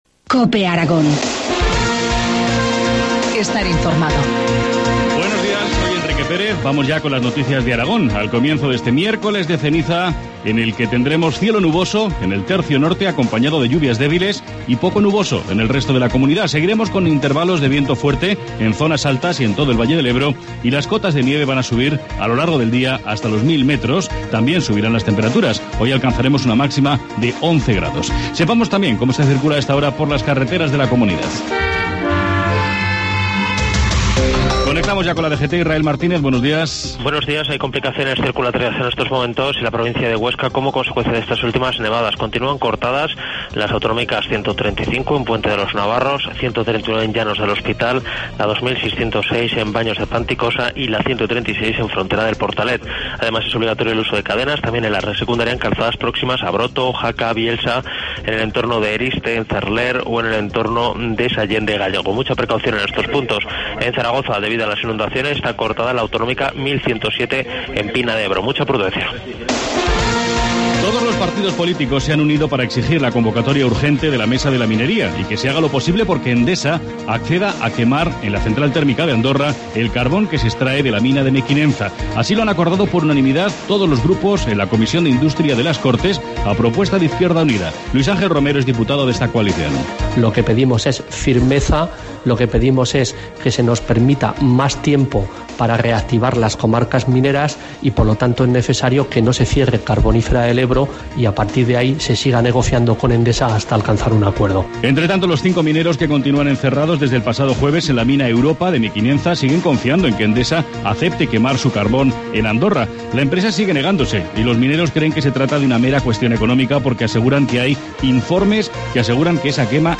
Informativo matinal, miércoles 13 de febrero, 7.25 horas